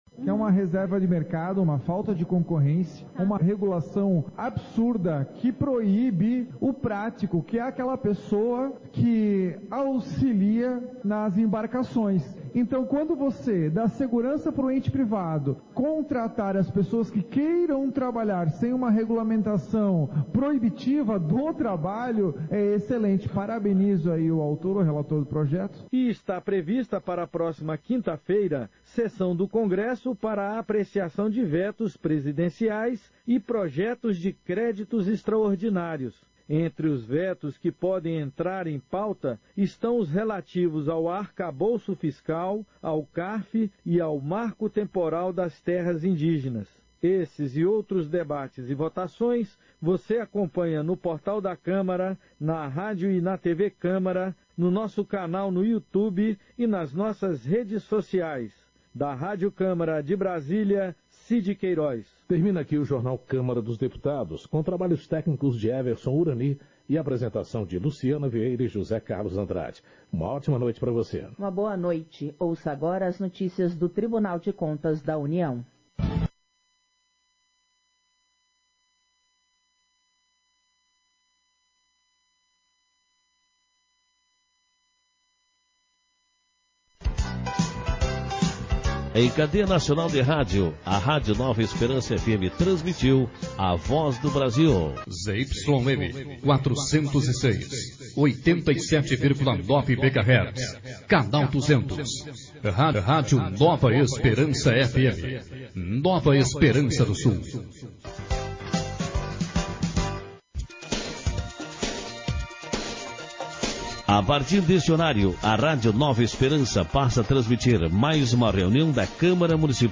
Sessão Ordinária 38/2023